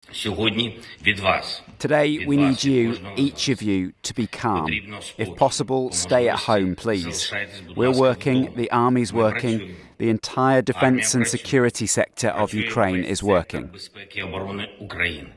Volodymyr Zelensky’s declared martial law and had this message for citizens.